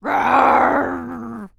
Female_Medium_Roar_01.wav